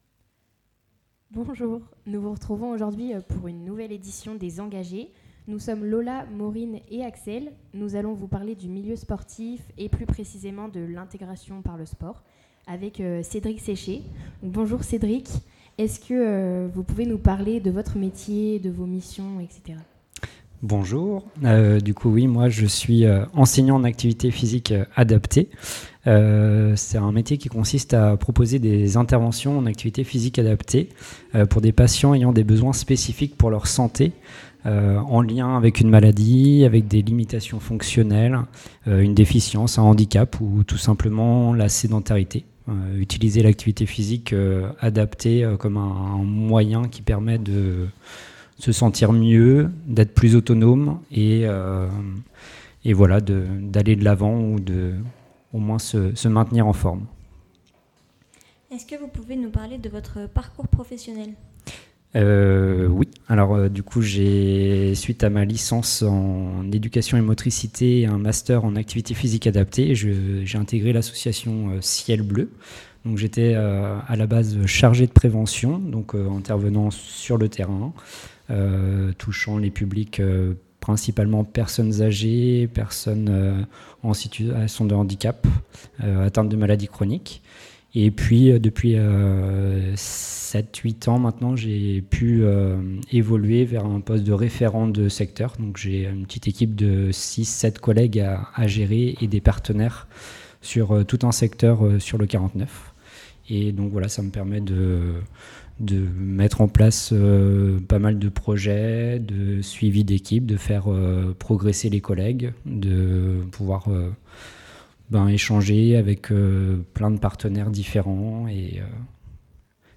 3 étudiantes en BUT Carrières sociales.